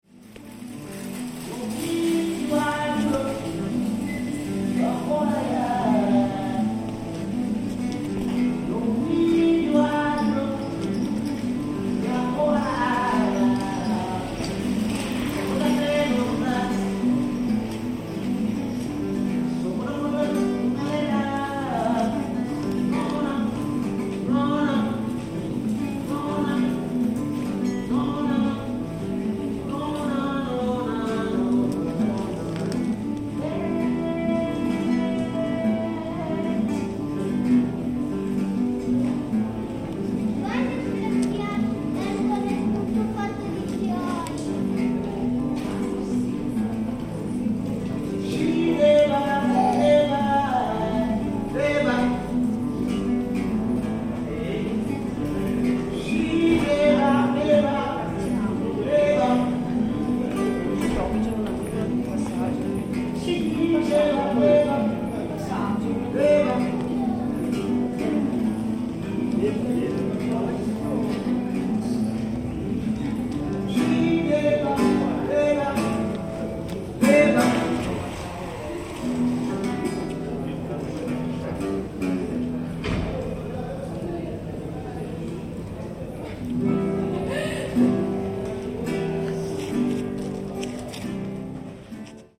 performing as busker in the same spot every single day. We had been enjoying listening to his beautiful voice and songs from his home every time we visited the city for several years until last year we finally stopped and spoke to him to hear a little bit of his story.